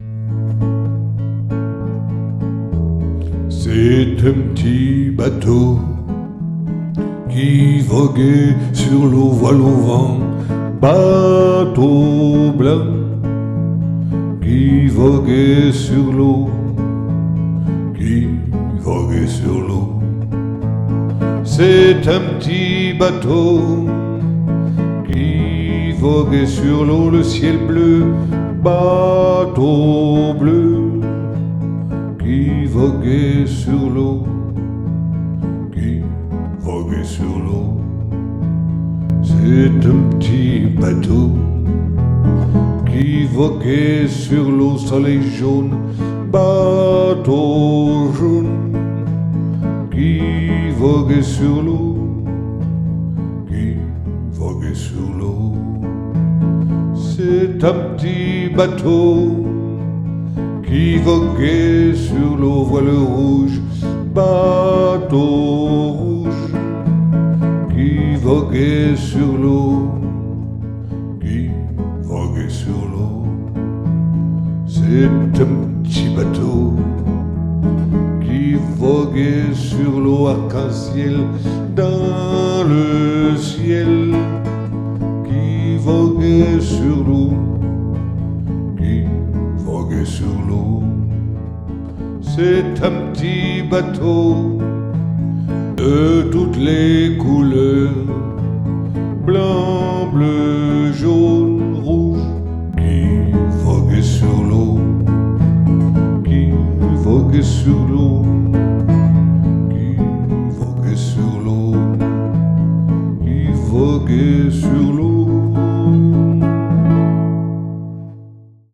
Enfantines